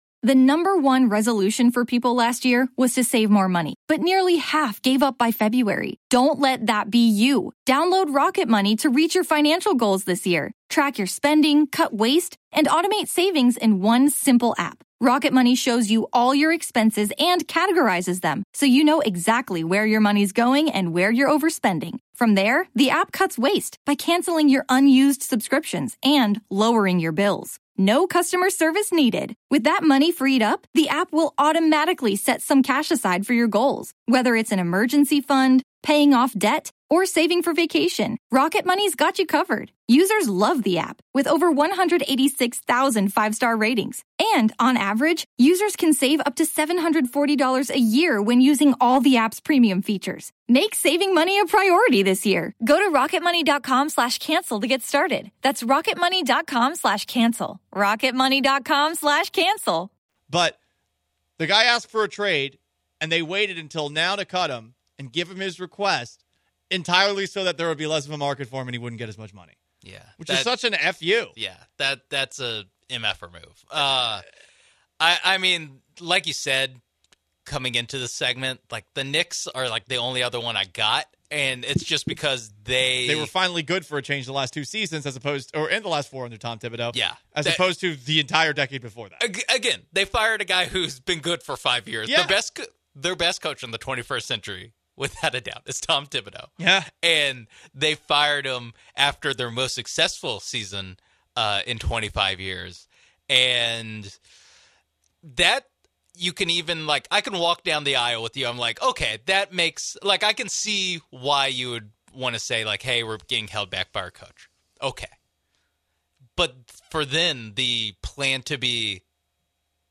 Let's dive into the momentum. NFL Insights: A Conversation